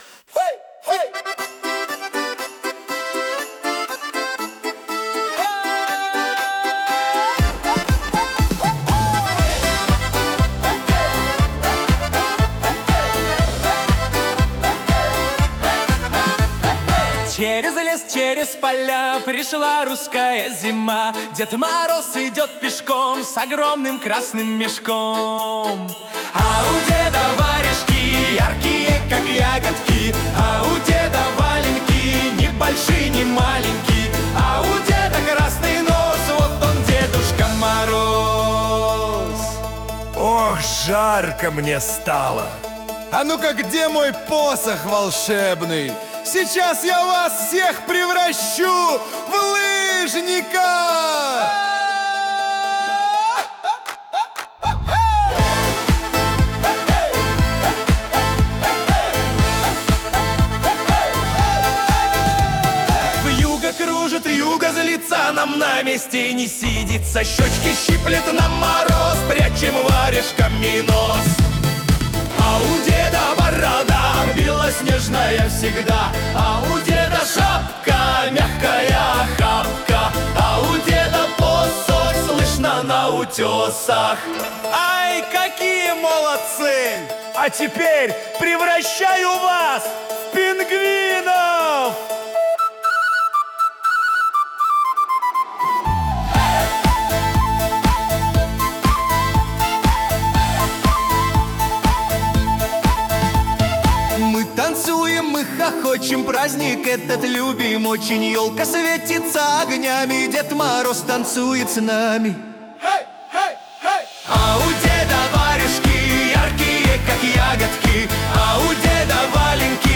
• Качество: Хорошее
• Жанр: Детские песни
песня игра